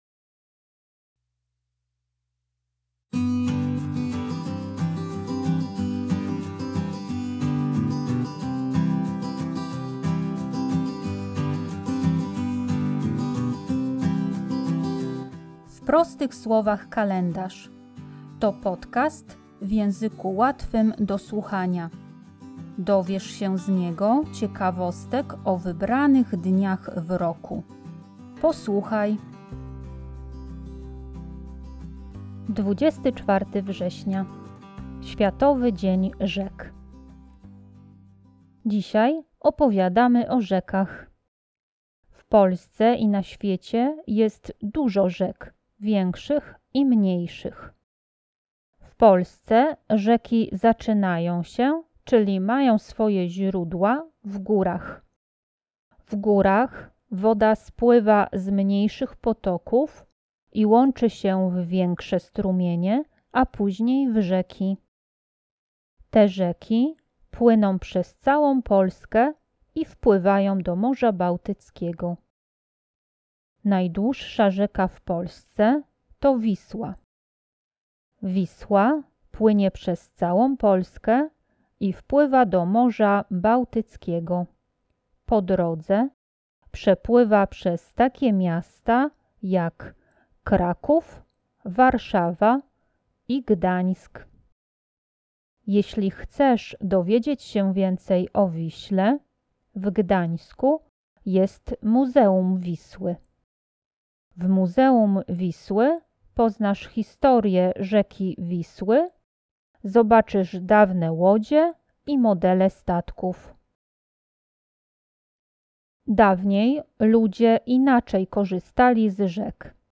W podcaście usłyszycie dźwięki płynącej wody.